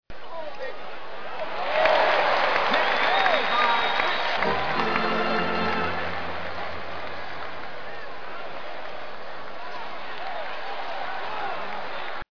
End of first quarter - basketball game
Category: Sound FX   Right: Personal